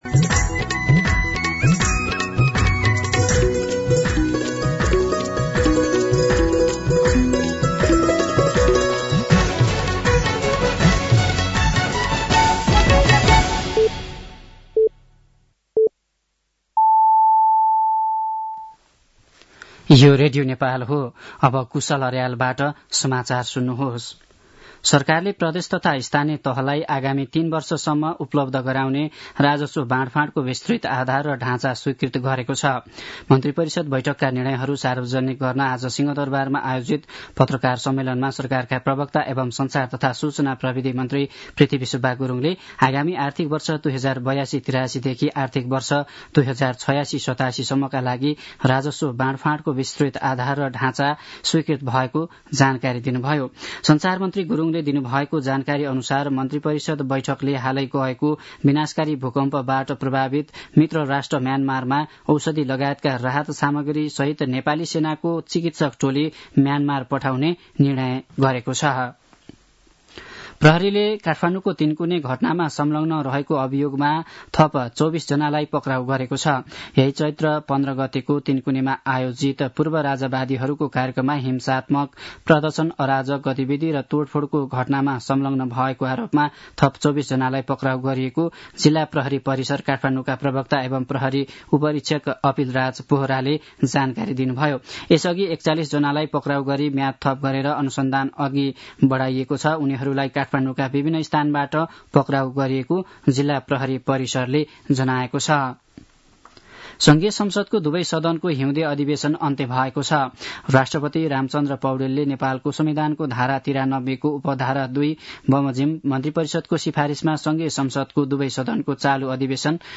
साँझ ५ बजेको नेपाली समाचार : १९ चैत , २०८१